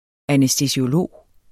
Udtale [ anεsdəsioˈloˀ ]